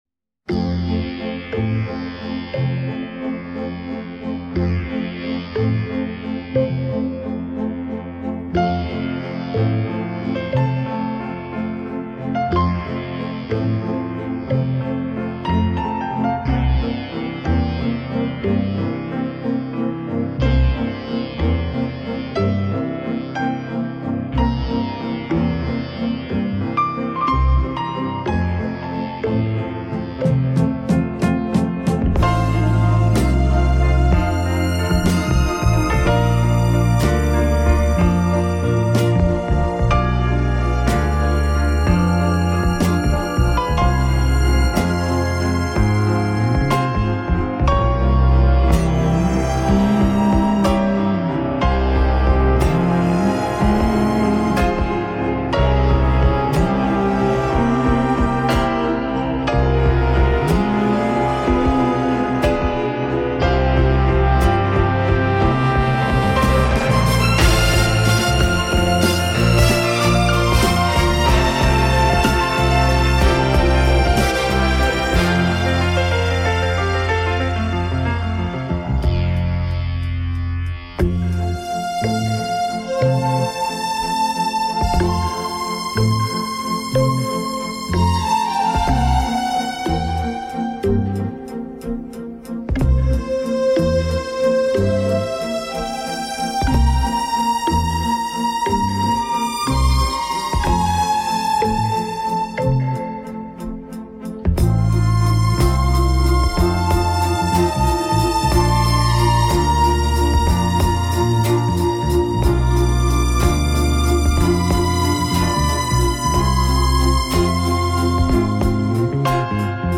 Genre: Easy Listening, Instrumental